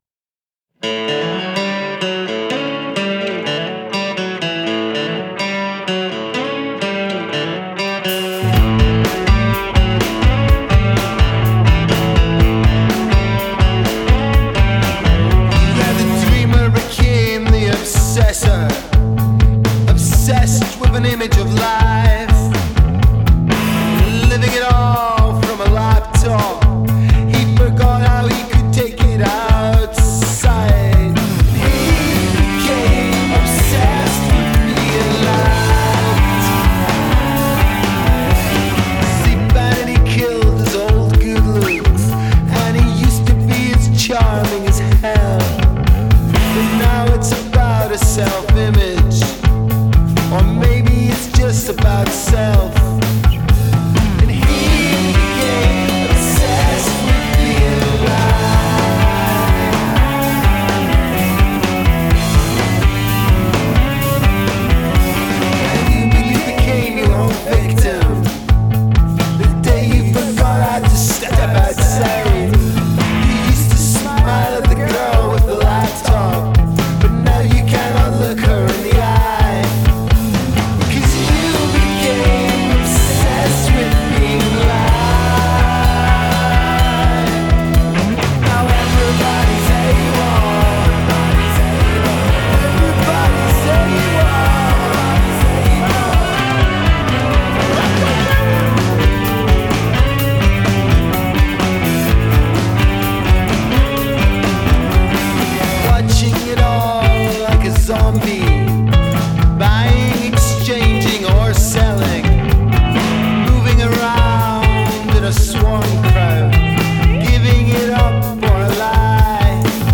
Genre: acoustic, experimental, folk rock